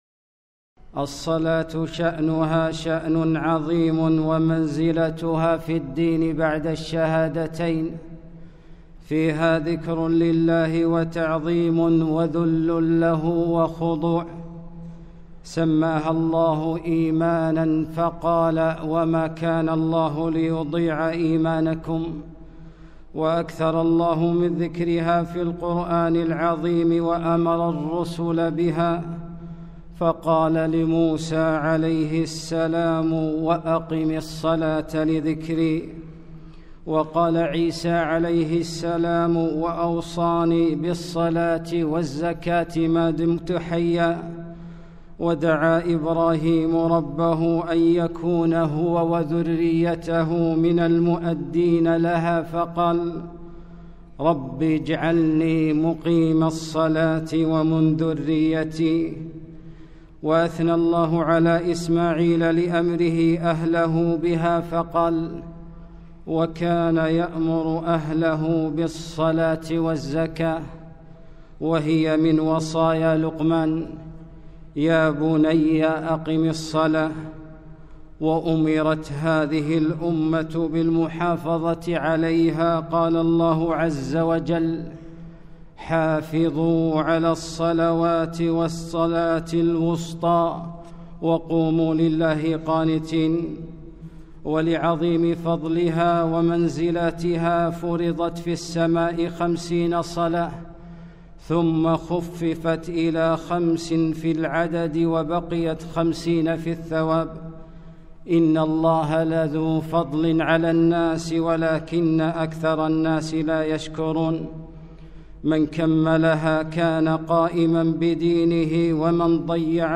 خطبة - يا تارك الصلاة!